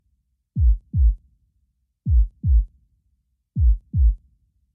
heartbeat3.mp3